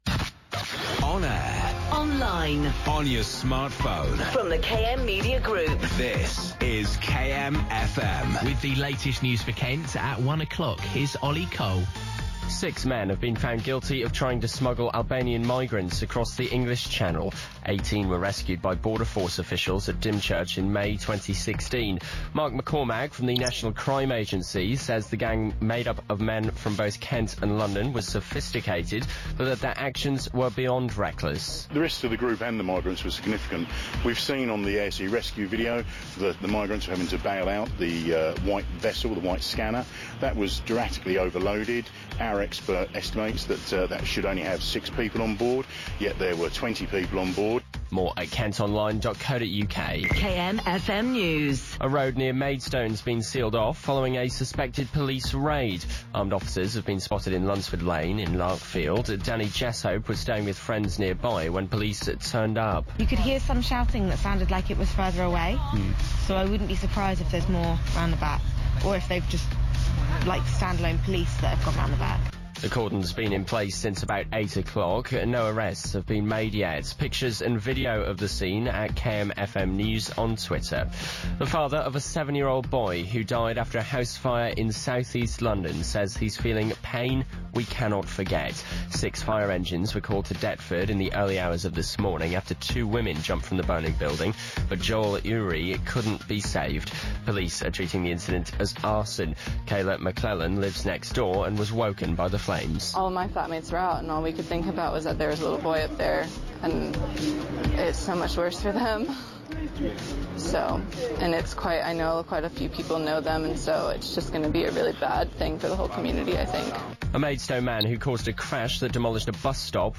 kmfm news